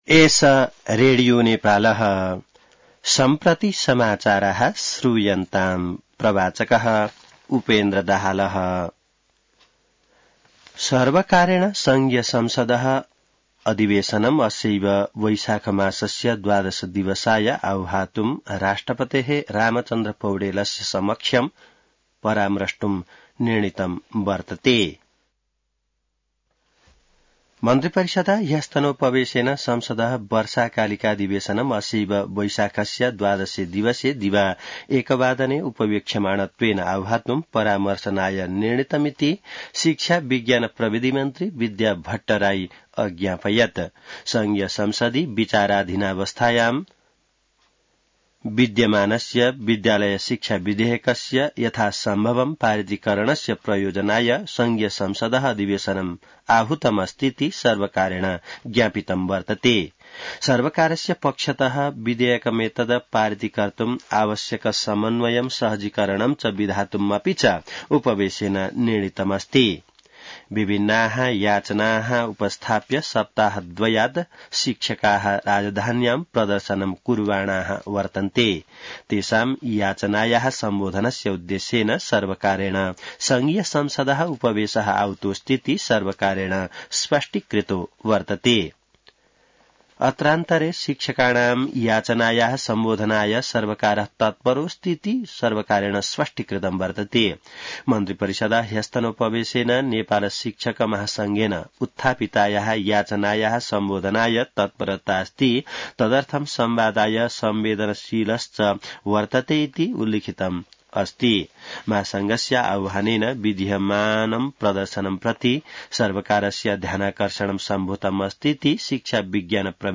संस्कृत समाचार : ३ वैशाख , २०८२